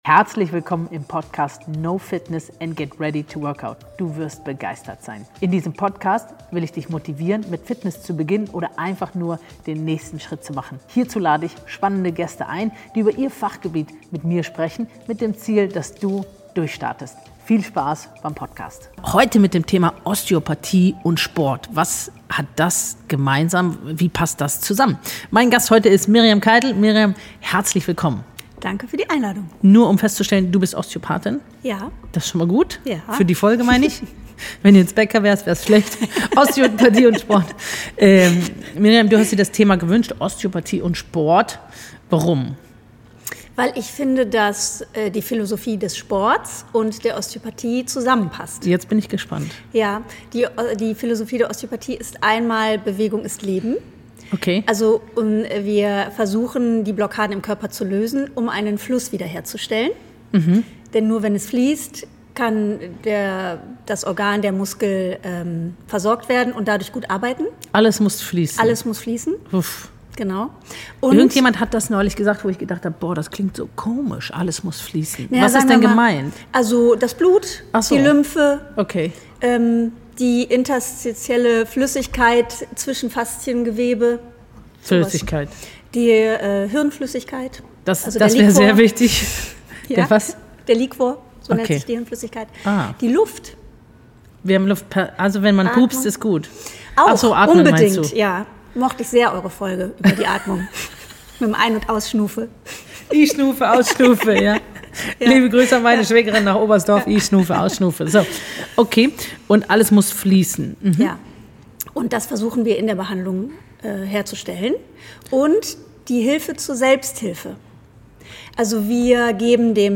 Die beiden diskutieren typische Patientenerwartungen, Erfolgsfaktoren für Heilung, und warum der Weg zur Gesundheit auch manchmal mit Humor beginnt.